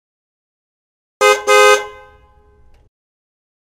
Car Horn Sound
transport
Car Horn